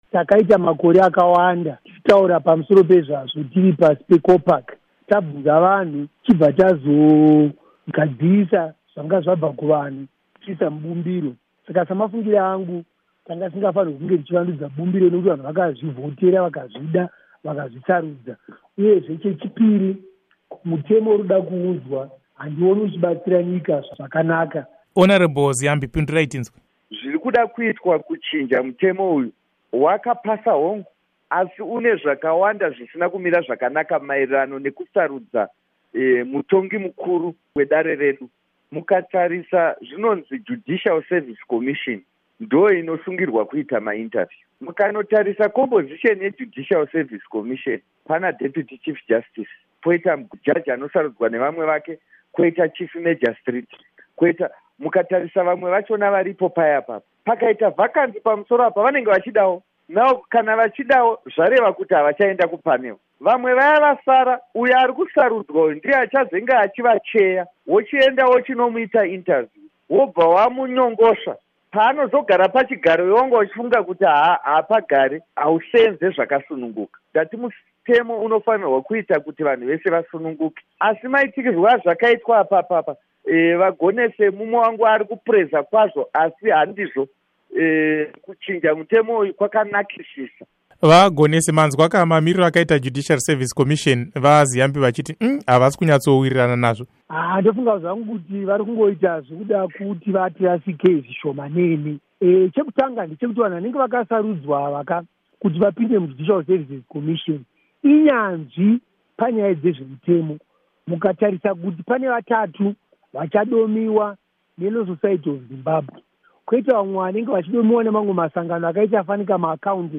Hurukuro naVaInnocent Gonese naVaZiyambi Ziyambi